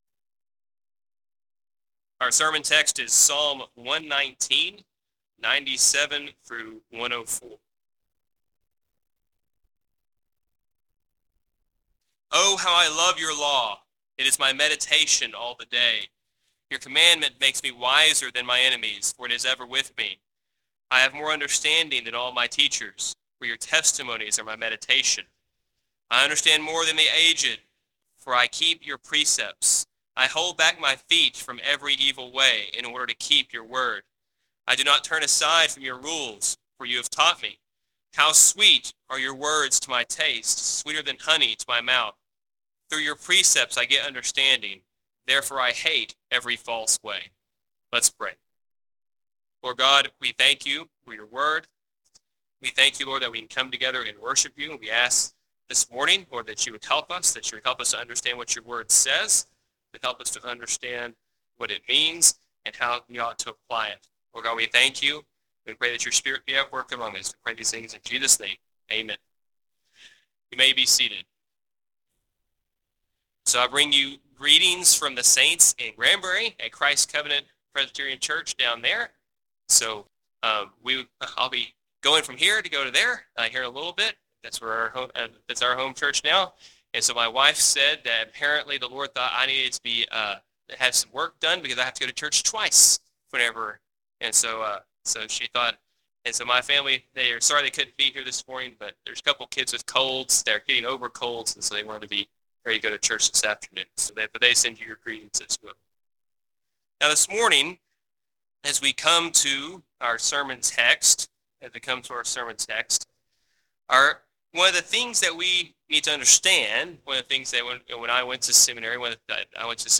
Audio Quality Note: We apologize for the poor quality of this audio. It was way worse, we did our best.
Psalm 119:97-104 Service Type: Sunday Sermon Audio Quality Note